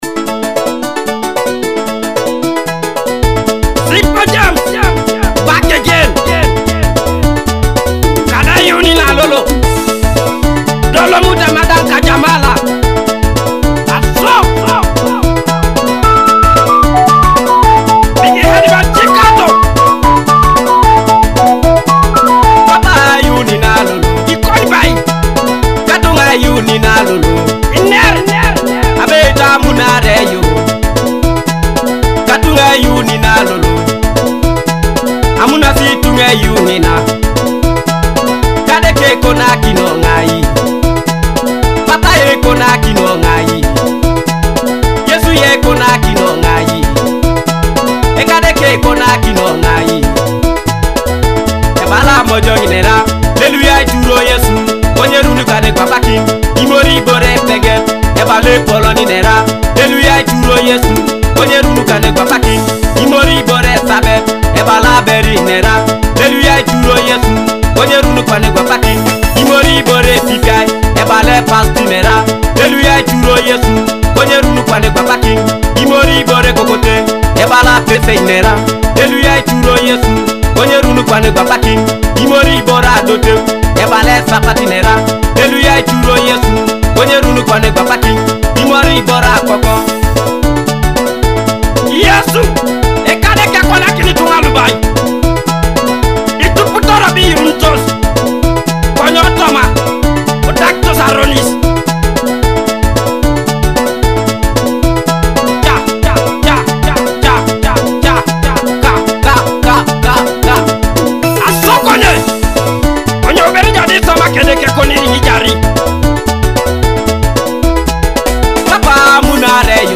a heartfelt Teso gospel song.
Uplifting song